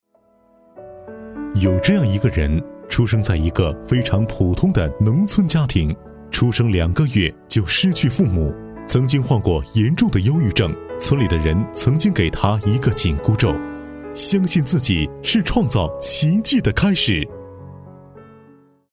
人物专题片配音是指以人物为中心，对人物事迹进行声文并茂的解说的过程，大部分人物专题片配音都是以抒情、感人的方式进行解说，在进行抒情类的人物专题片配音时配音员要多熟悉稿子，溶入感情色彩，要把握好度，否则就太做作。
男声配音